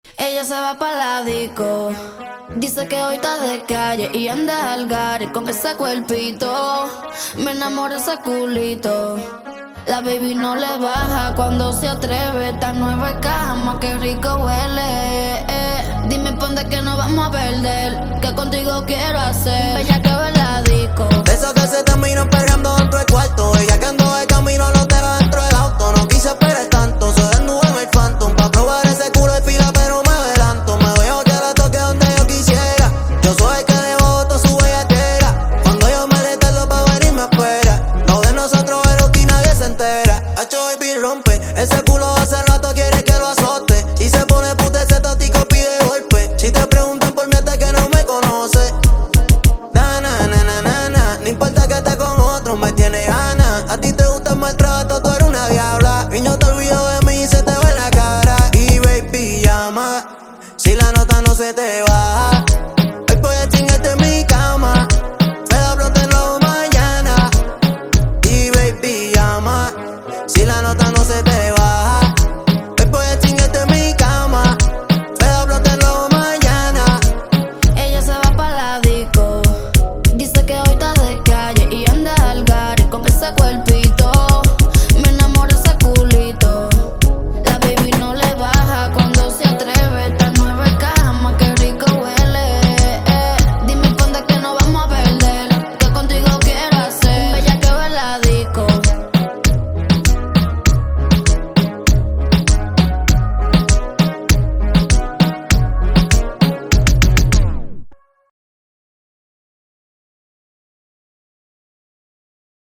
ژانر: رپ